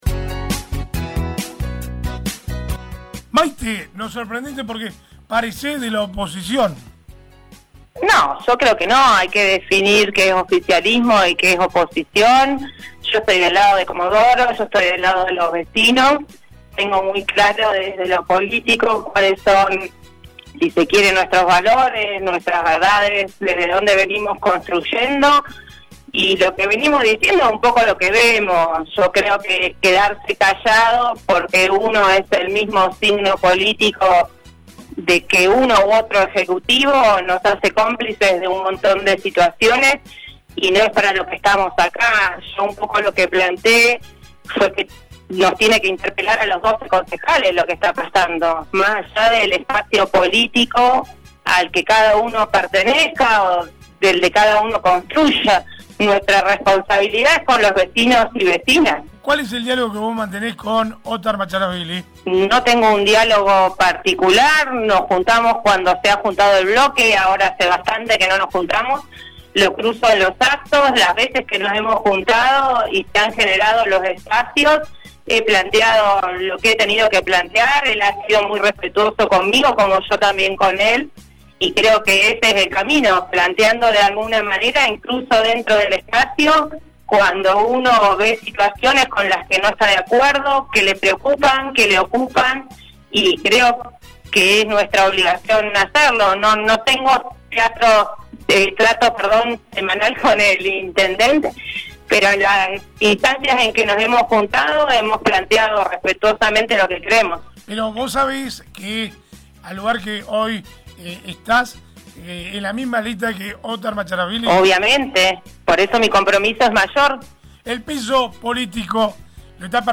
La concejal de Arriba Chubut, Maite Luque, dijo a través de su cuenta de la red social X, que Comodoro está perdiendo peso político. Y en ese sentido dialogó con LA MAÑANA DE HOY